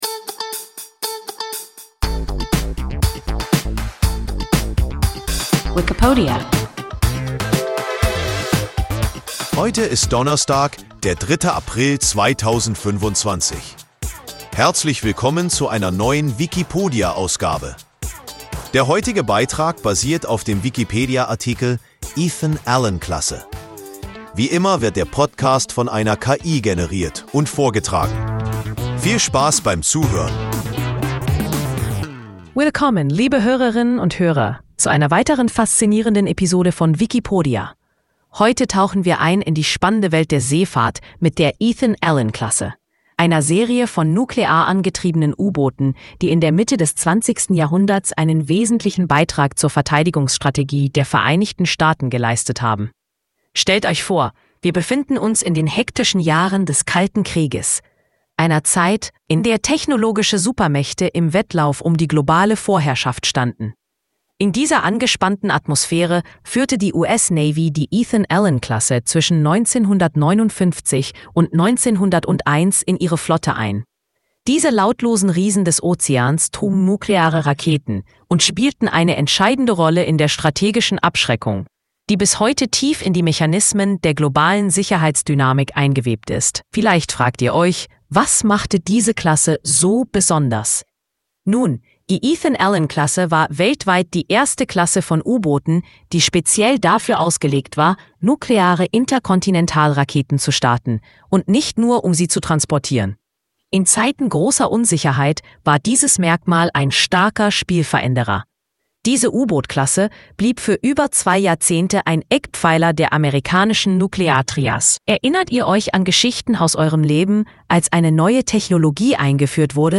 Ethan-Allen-Klasse – WIKIPODIA – ein KI Podcast